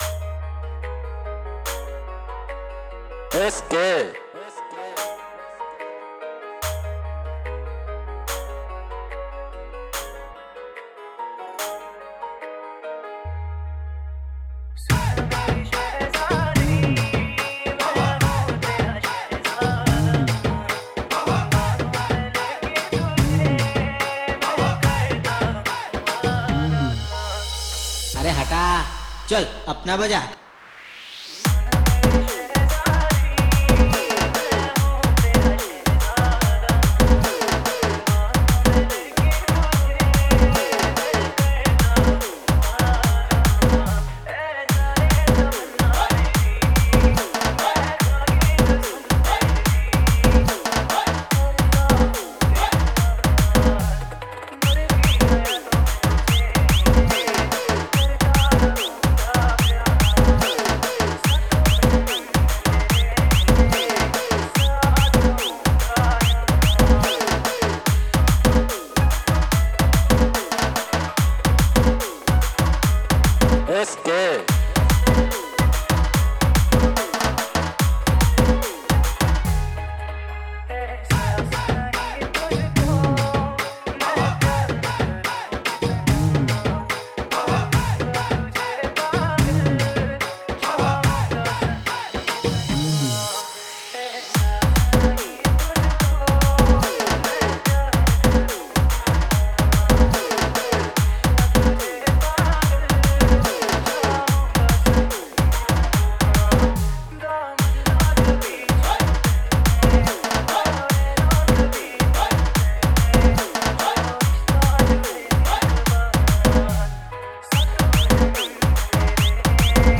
Category:  New Hindi Dj Song 2020